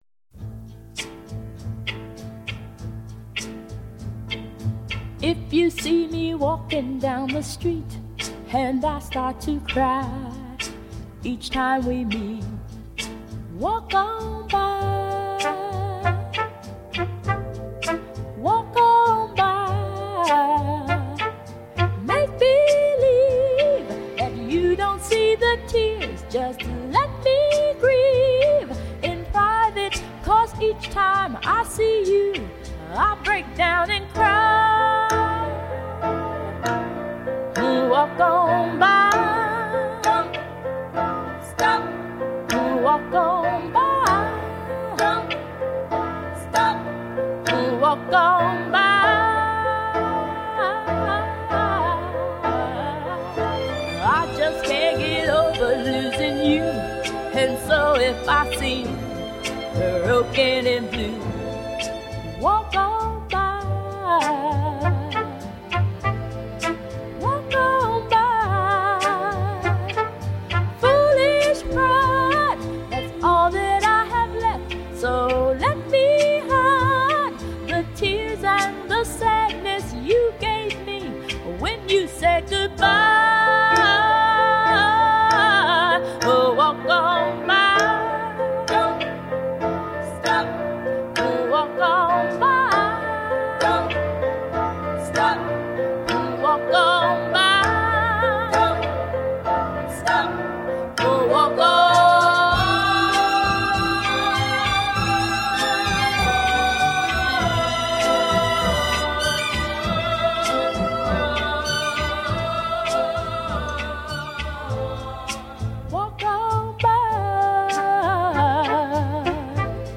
and one of my favorite pop tunes by this team